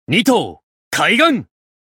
刀剑乱舞_Buzen-doubleattackcallout.mp3